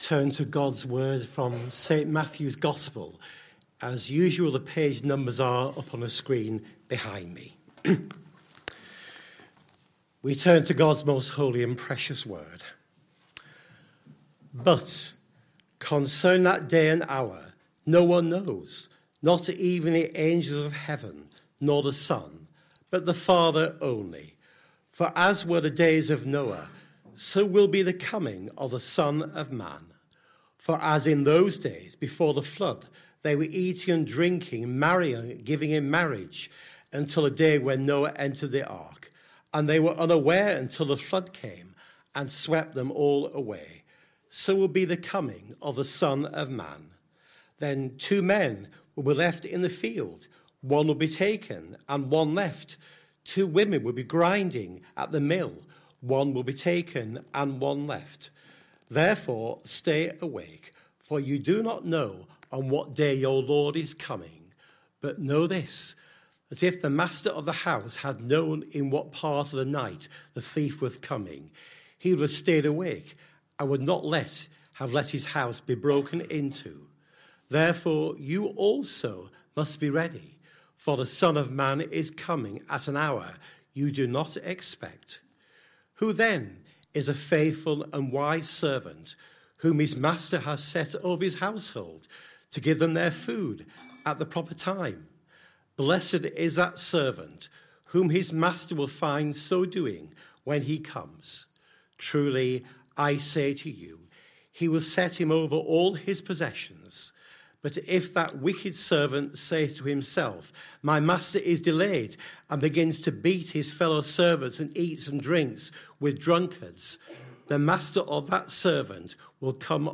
Sunday PM Service Sunday 7th December 2025 Speaker